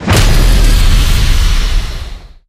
controller_final_hit.ogg